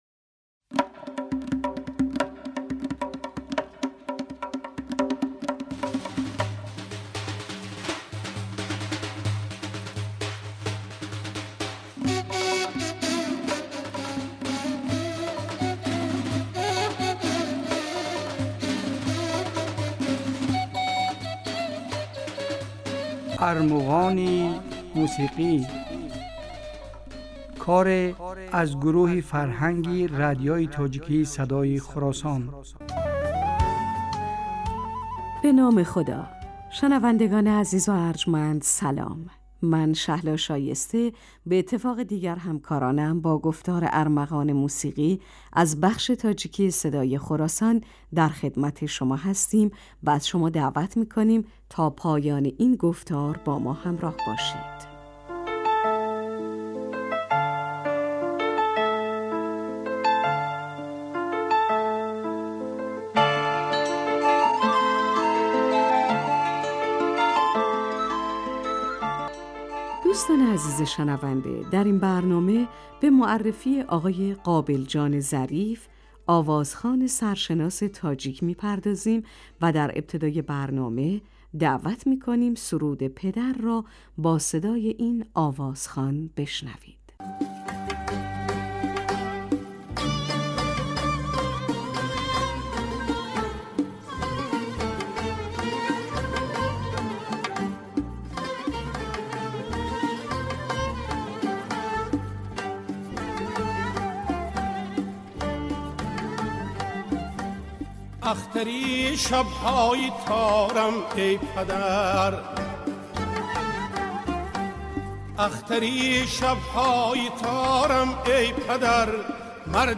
Армуғони мусиқӣ асари аз гурӯҳи фарҳанги радиои тоҷикии Садои Хуросон аст. Дар ин барномаҳо кӯшиш мекунем, ки беҳтарин ва зеботарин мусиқии тоҷикӣ ва...